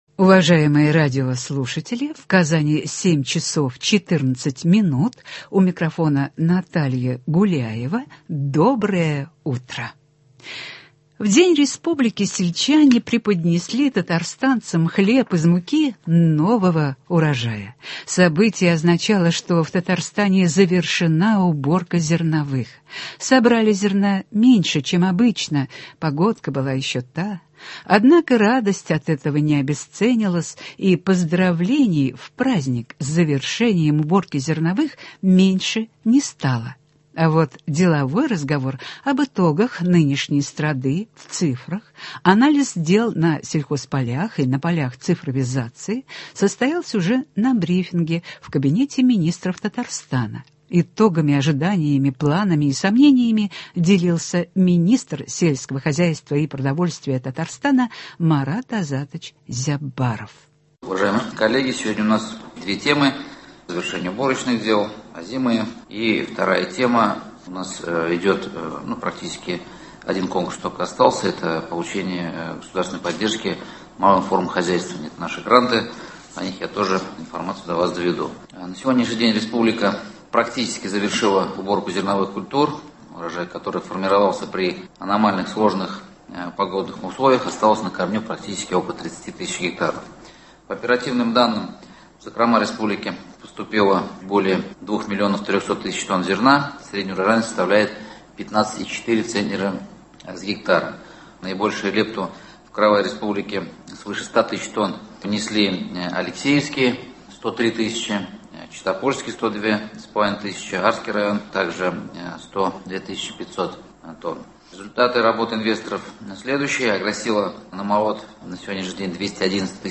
На сегодня республика практически завершила уборку зерновых культур, урожай которых формировался при аномально сложных погодных условиях. Предлагаем послушать заместителя премьер-министра, министра сельского хозяйства и продовольствия республики Марата Зяббарова.